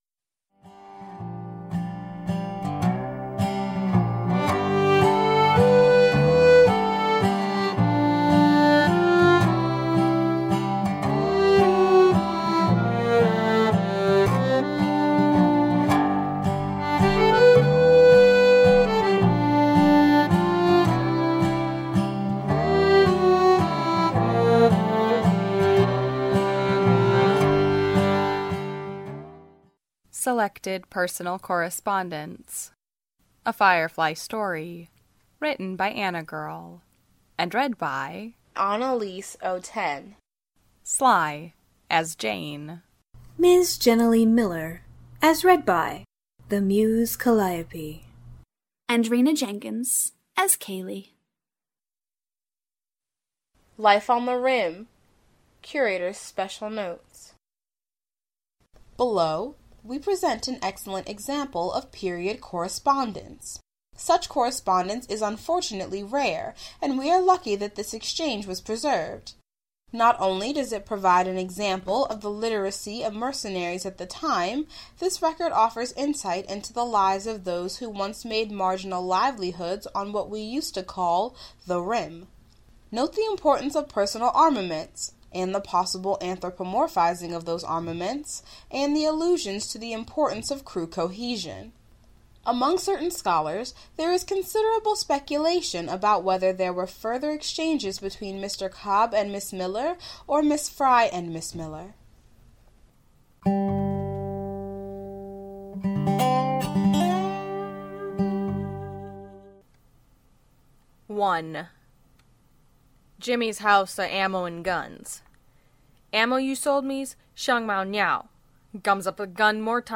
podfic